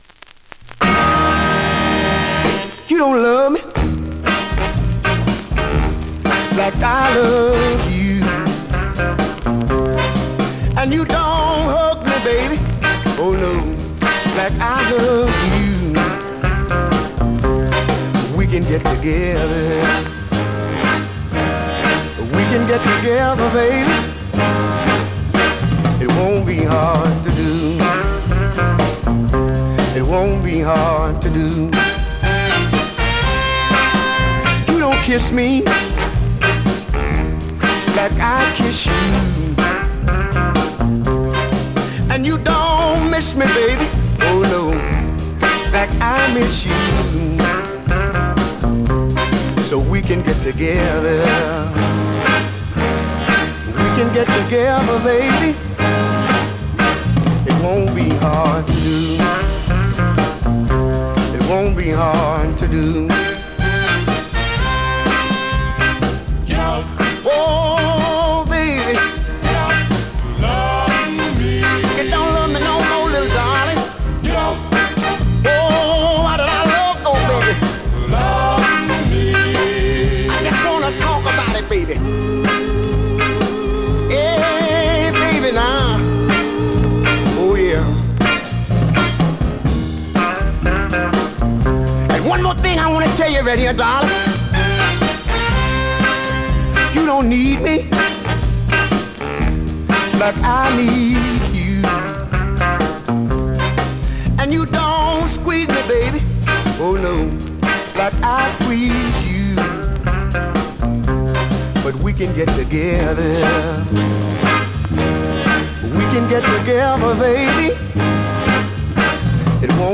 Vinyl has a few light marks plays great .
R&B, MOD, POPCORN , SOUL